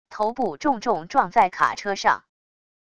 头部重重撞在卡车上wav音频